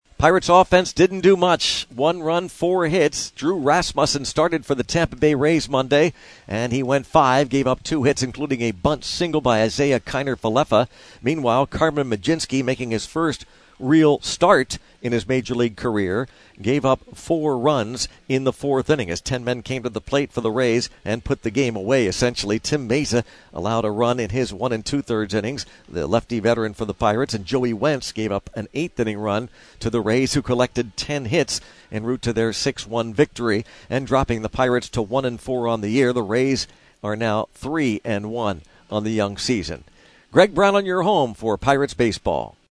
3-31-recap.mp3